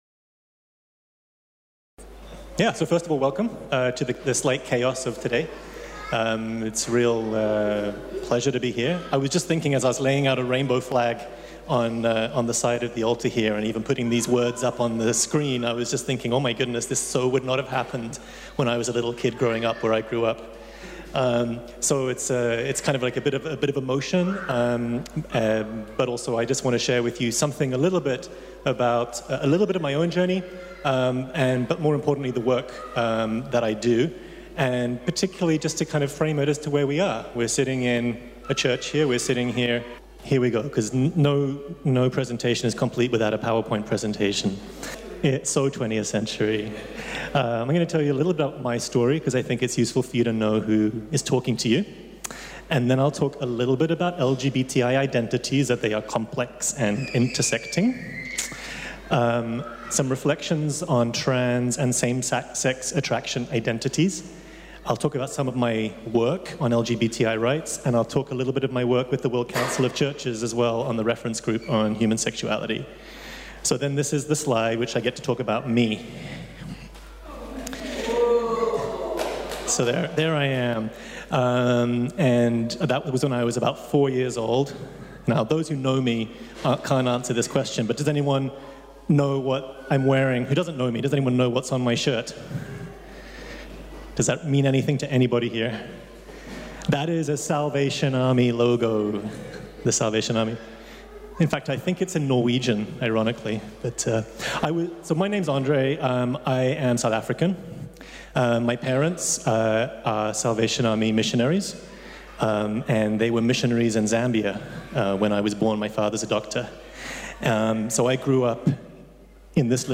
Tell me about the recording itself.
Weekly Forums at ELCG – after worship, in the Sanctuary – 12:45 to 13:30